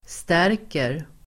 Uttal: [st'är:ker]